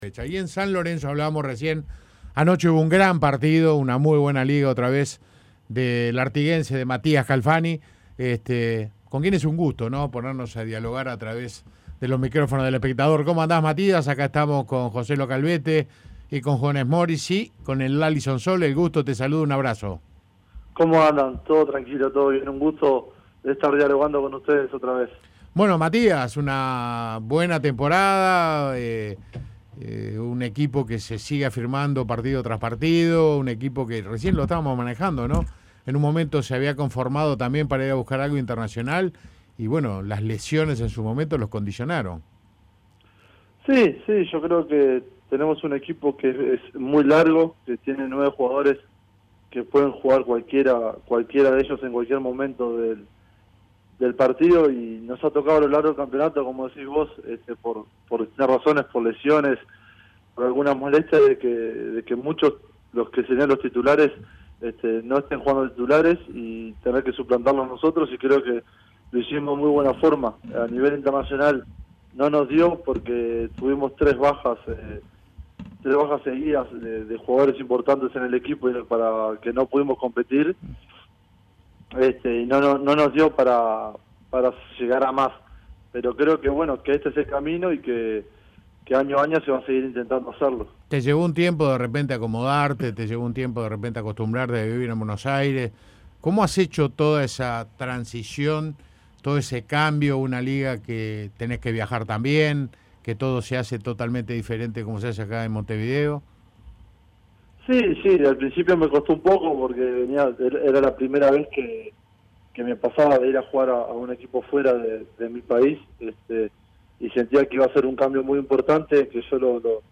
Además, se refirió a una posible transferencia a la NBA, así como a la selección, que a fines de agosto será local en la AmeriCup. Entrevista completa.